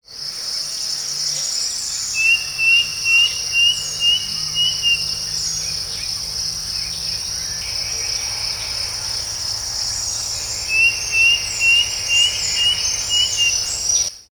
Striped Cuckoo (Tapera naevia)
Location or protected area: Parque Nacional Iguazú
Detailed location: Pasarela a Garganta del Diablo
Condition: Wild
Certainty: Recorded vocal